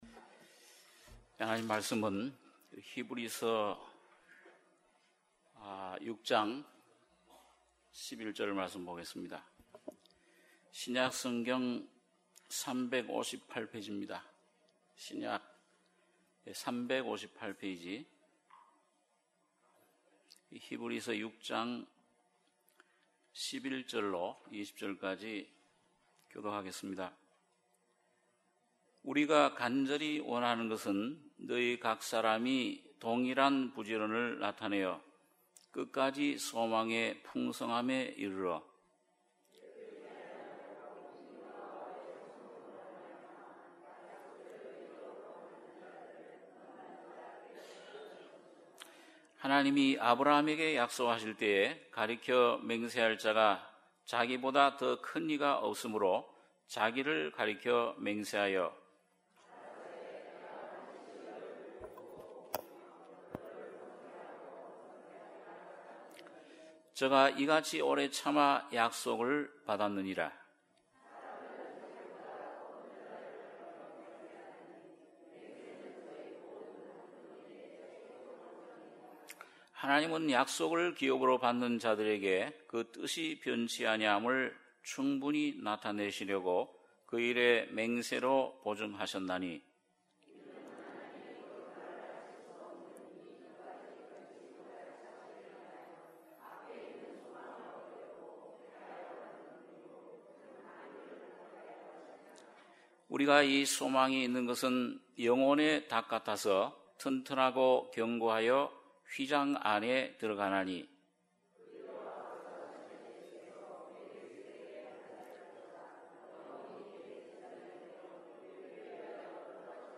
주일예배 - 히브리서 6장 11절-20절